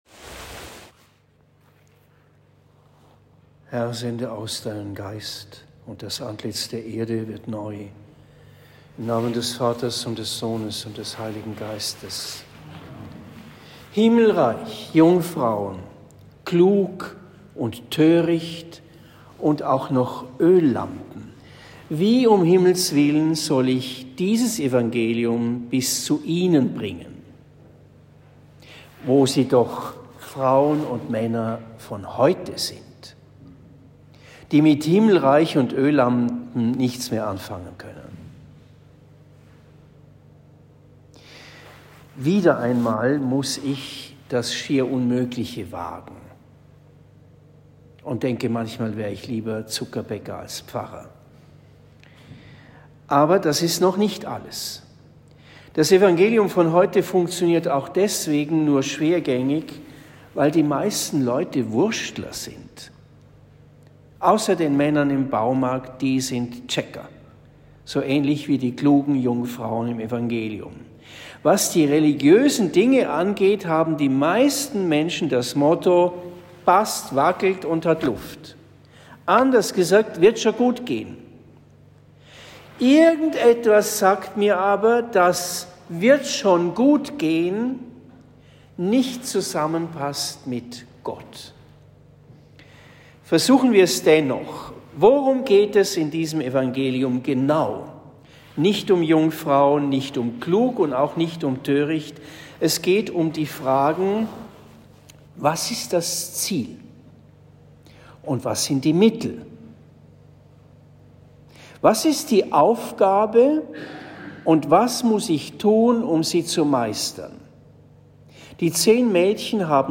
Sonntag im Jahreskreis (A) – (Mt 25,1-13) Predigt in Marienbrunn am 11. November 2023